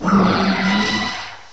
cry_not_zeraora.aif